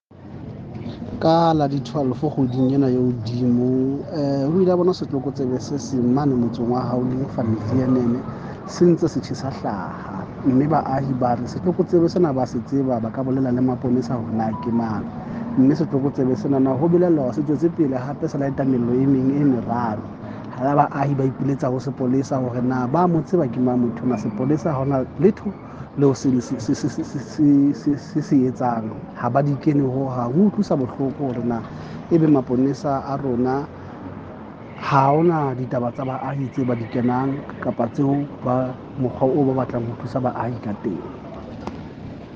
Sesotho by Cllr Moshe Lefuma.